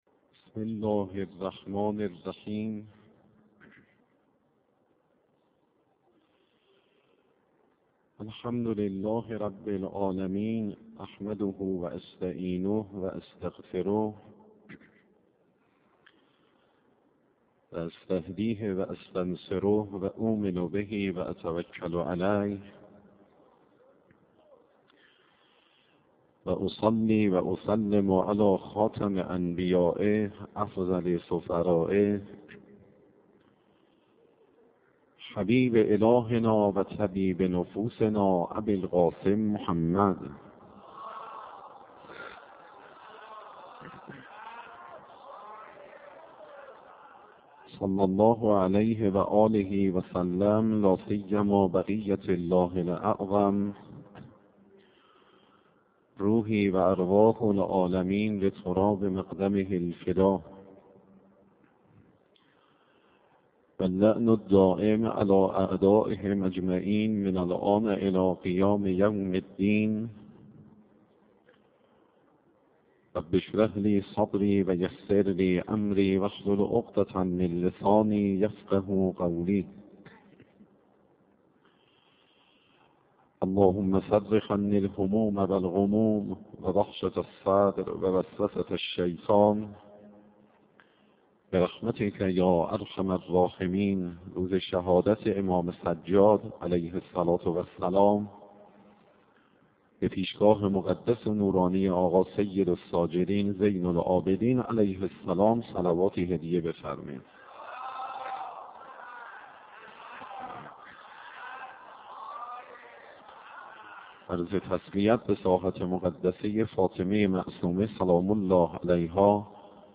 مدت سخنرانی